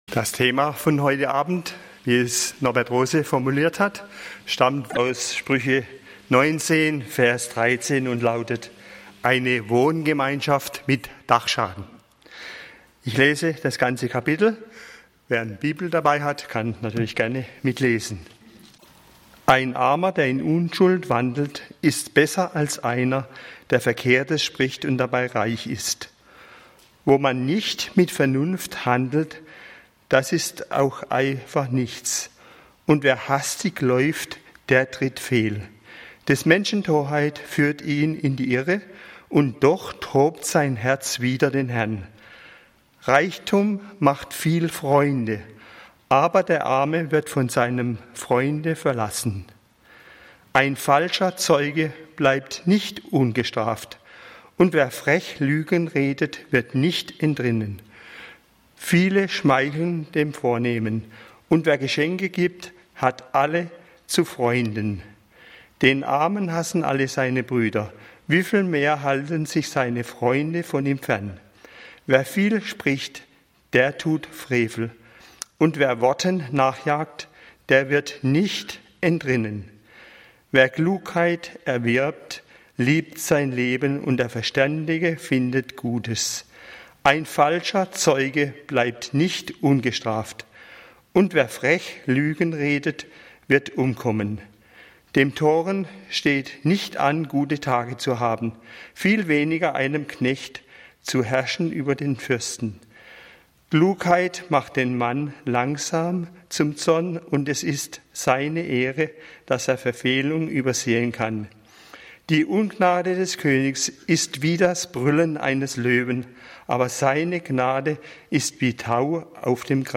Aus dem Schatten ins Licht (1. Joh.1, 5-10) - Gottesdienst